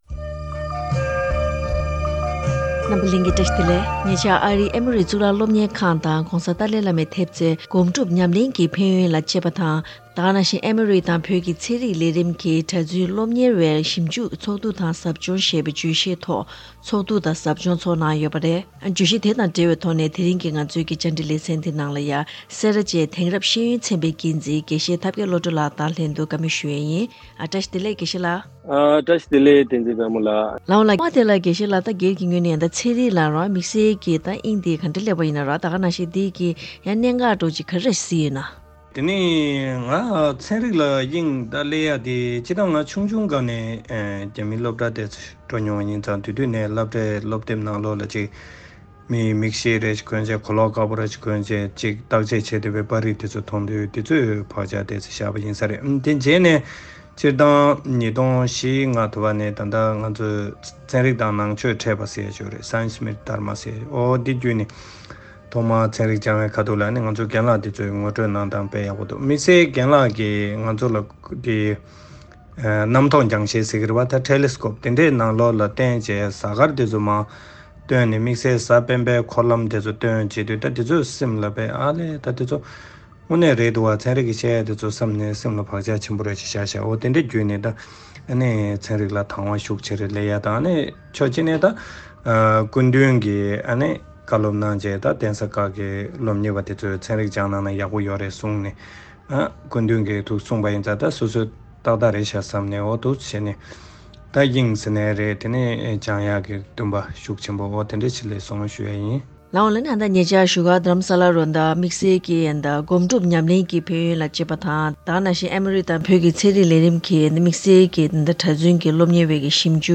བཀའ་དྲི་ཞུས་པ་ཞིག་གསན་རོགས་གནང་།